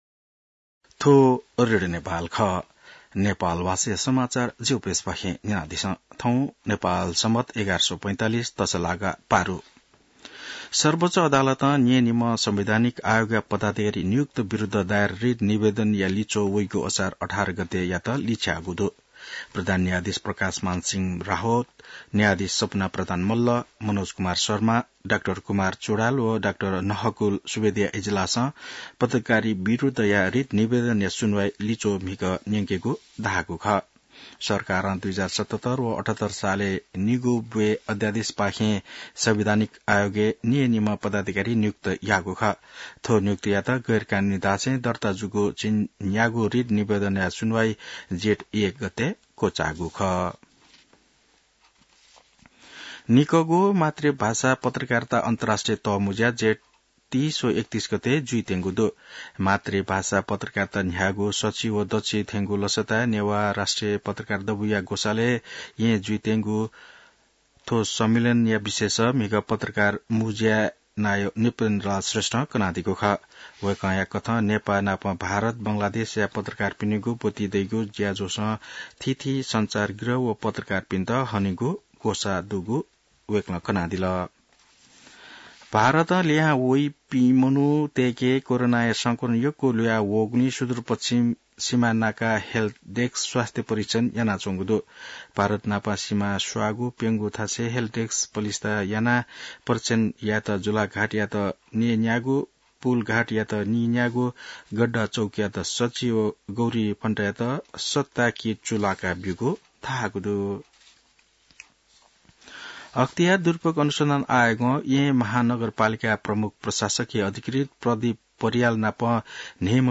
नेपाल भाषामा समाचार : २९ जेठ , २०८२